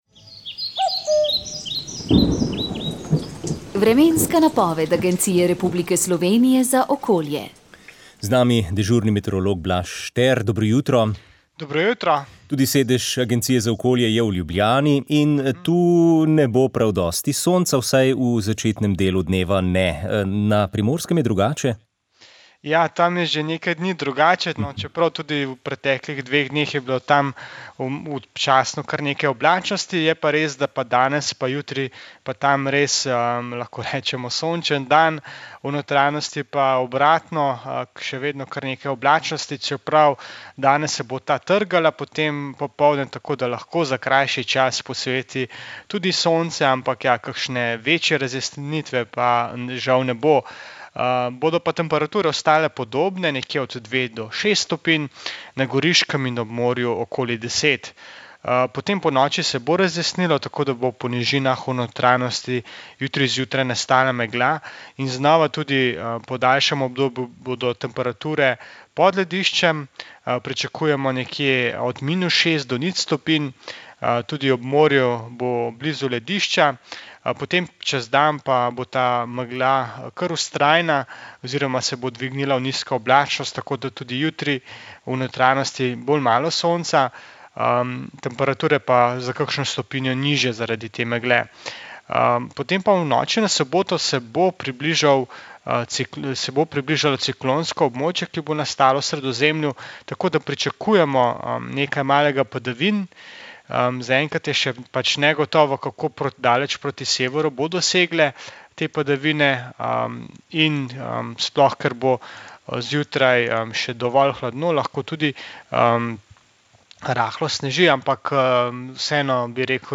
Pevci in pevke ljudskih pesmi Folklorne skupine Karavanke iz Tržiča so 18. maja v domu krajanov Lom pod Storžičem pripravili tematski koncert z naslovom Ohcet bo. Obe društveni pevski skupini sta zbrane popeljali skozi ženitovanjske šege in navade.
Odlomke s prireditve je prinesla današnja oddaja o ljudski glasbi.